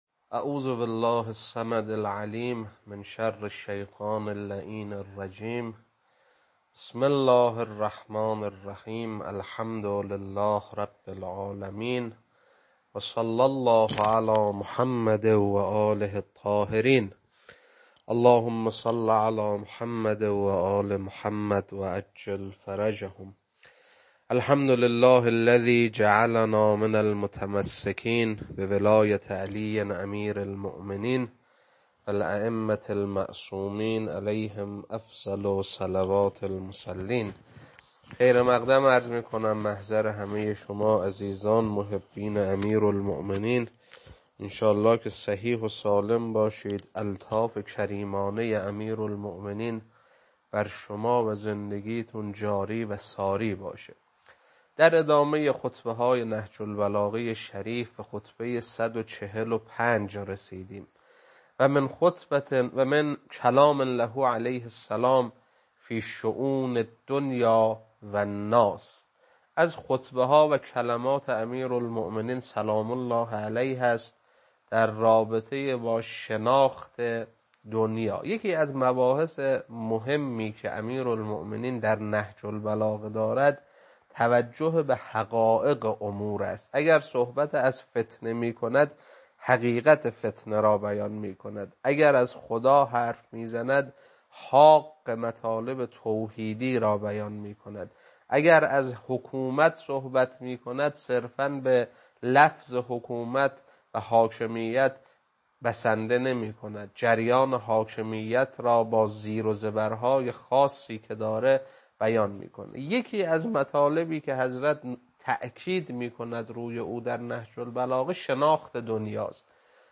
خطبه 145.mp3
خطبه-145.mp3